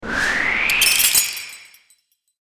魔法 | 無料 BGM・効果音のフリー音源素材 | Springin’ Sound Stock
氷魔法.mp3